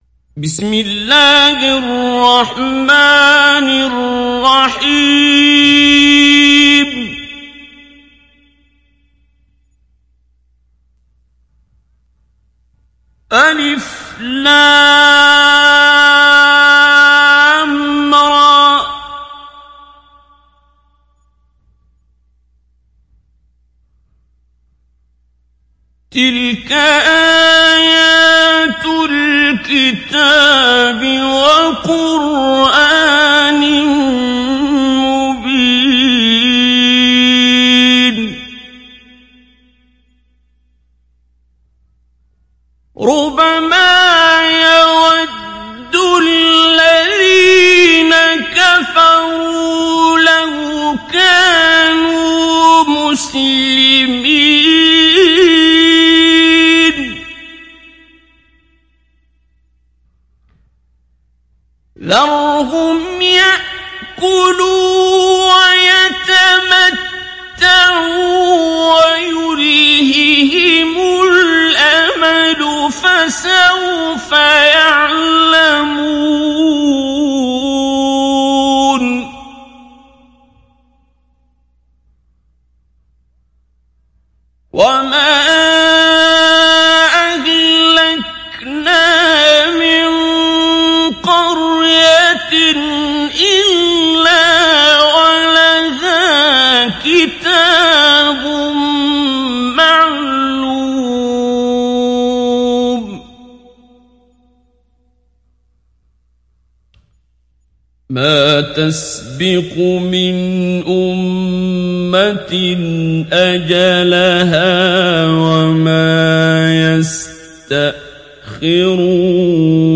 Mujawwad